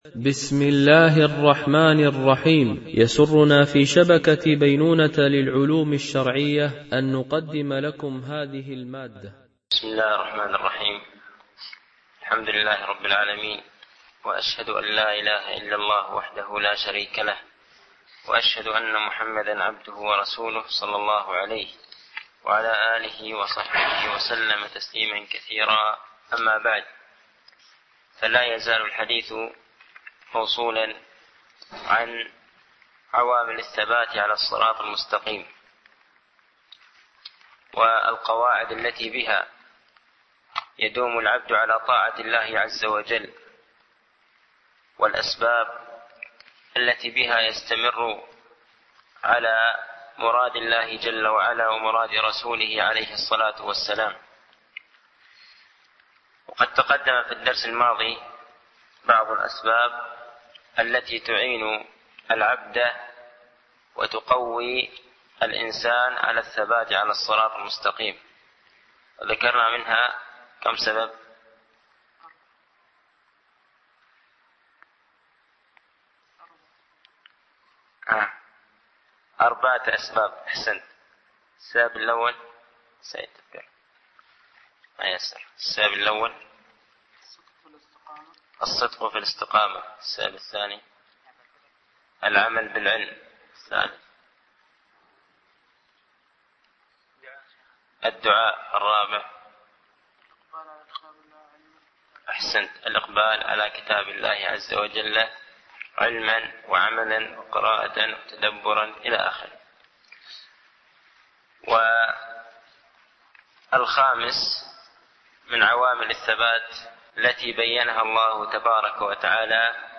) الألبوم: شبكة بينونة للعلوم الشرعية التتبع: 127 المدة: 51:25 دقائق (11.81 م.بايت) التنسيق: MP3 Mono 22kHz 32Kbps (CBR)